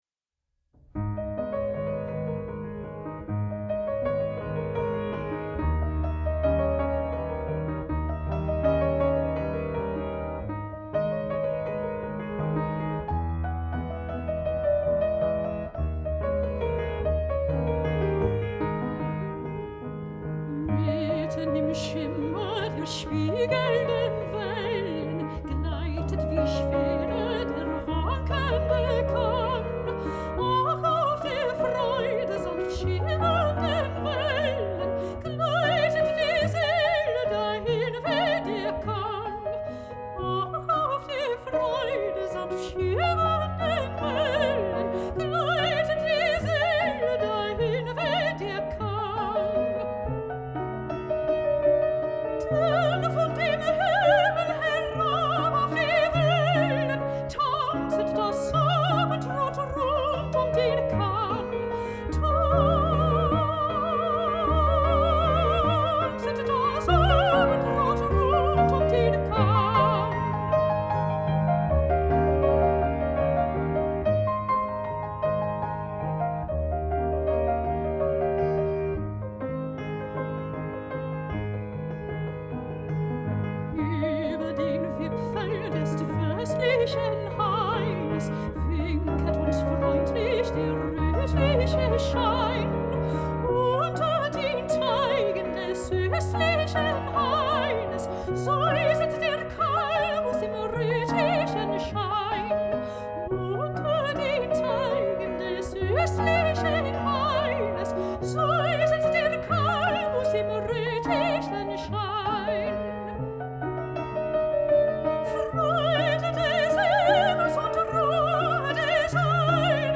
Classical Repertoire